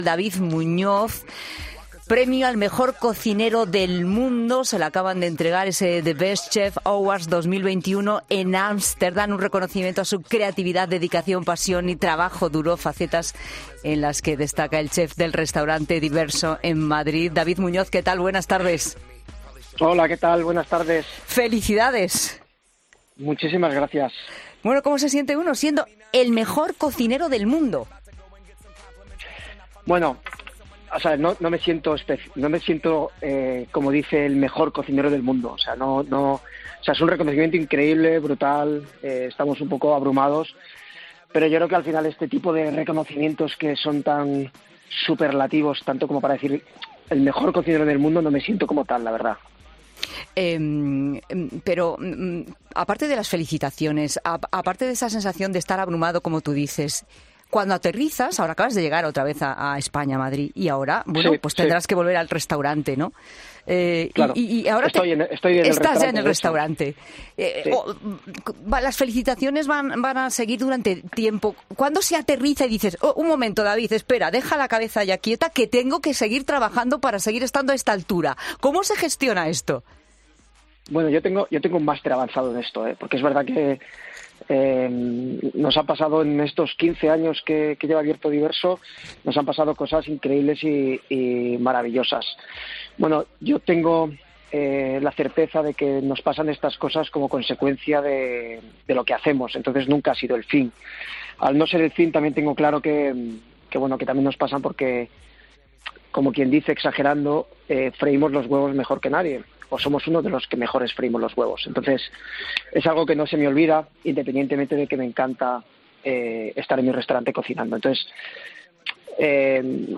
Me encanta estar en mi restaurante cocinando", ha asegurado el chef en los micrófonos de 'La Tarde' de COPE.